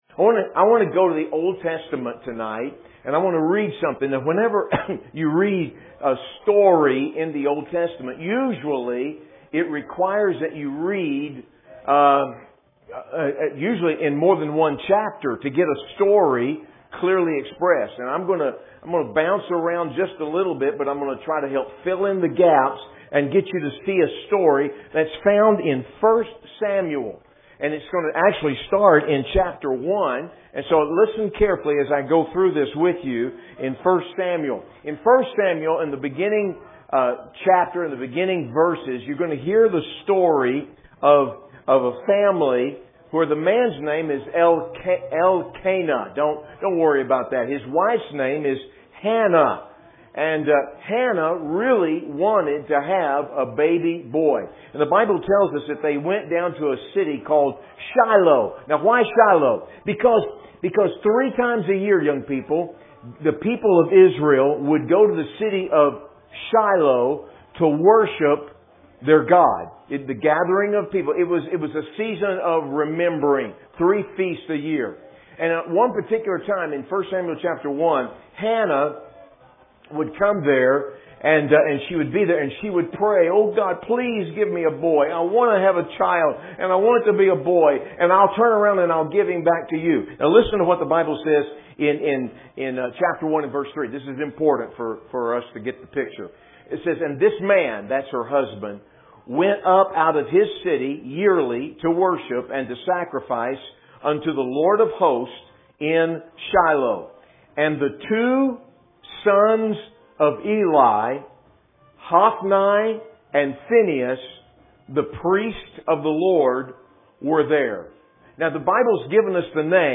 Teen Battle Week